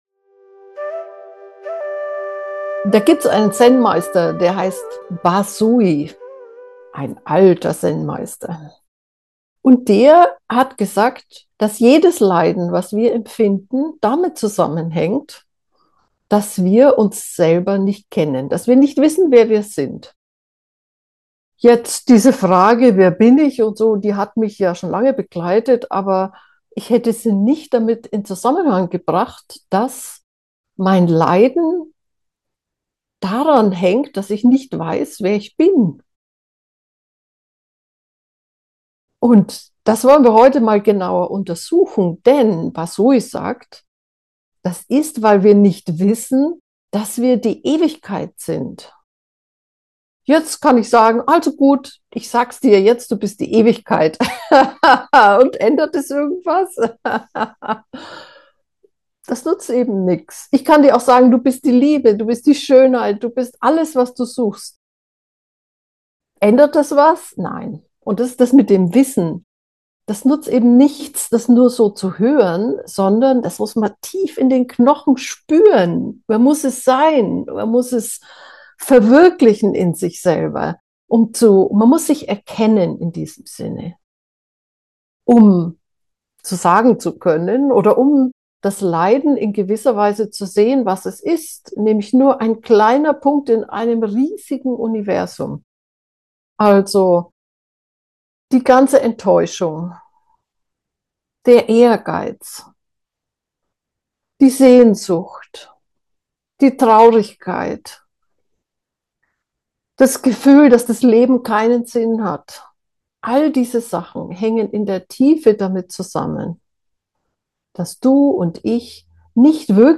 Geführte Meditationen Folge 243: Wer bin ich?